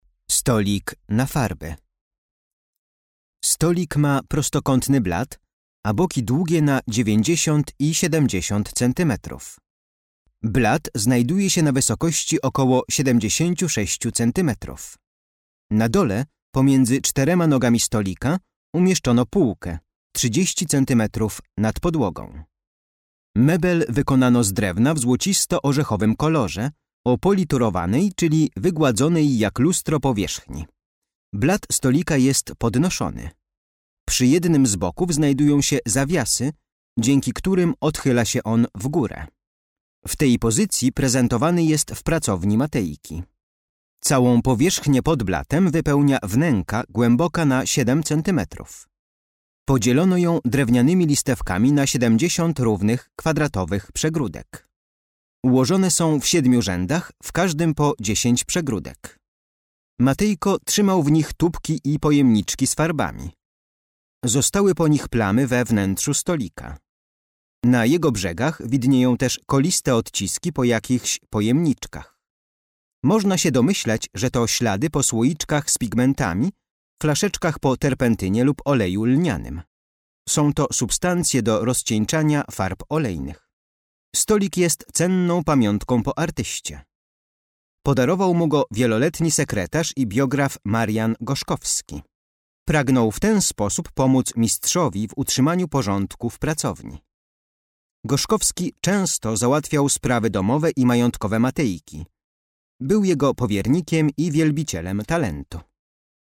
Audiodeskrypcja dla wybranych eksponatów z kolekcji MNK znajdujących się w Domu Jana Matejki.